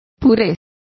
Complete with pronunciation of the translation of puree.